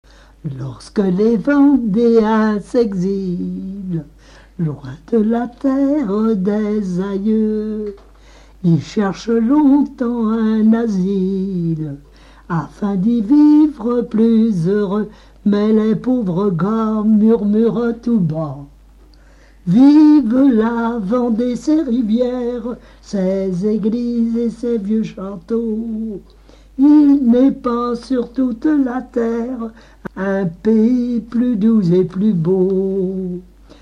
Mémoires et Patrimoines vivants - RaddO est une base de données d'archives iconographiques et sonores.
Genre strophique
Pièce musicale inédite